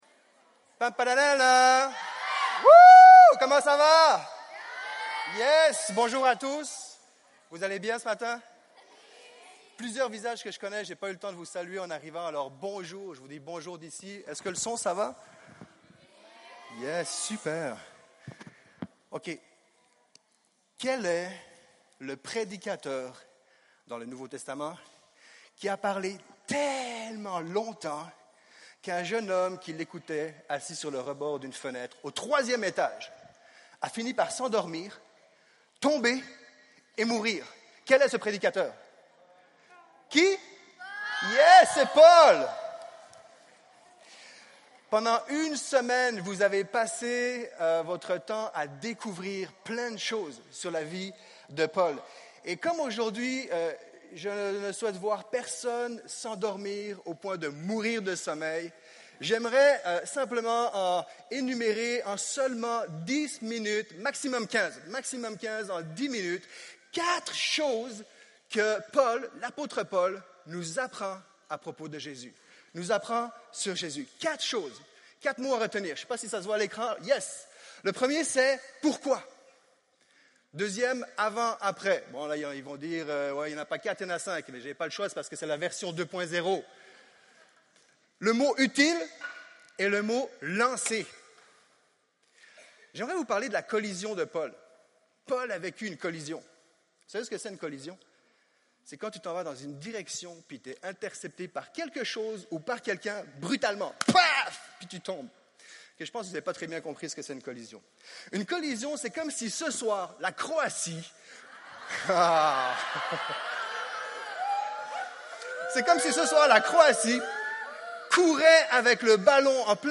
Culte du 15 juillet